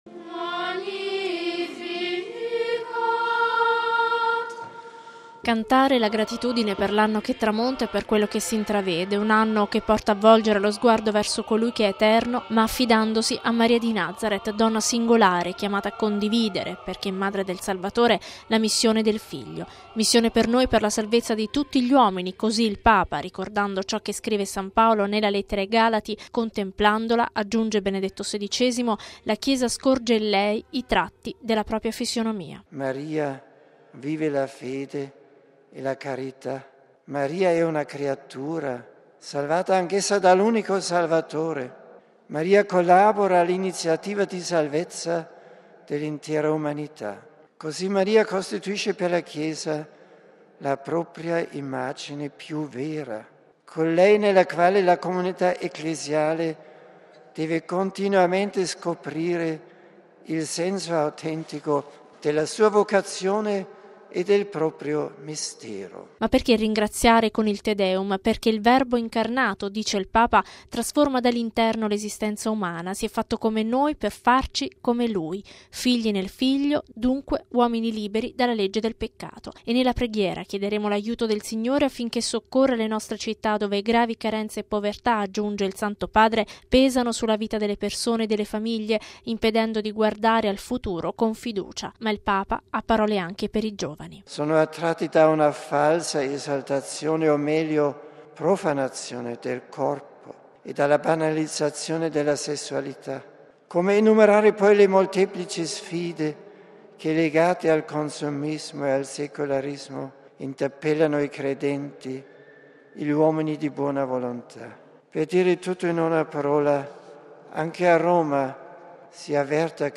Abbiamo bisogno della speranza cristiana per vincere il deficit di fiducia dell'umanità: così il Papa durante i Primi Vespri e il Te Deum di fine anno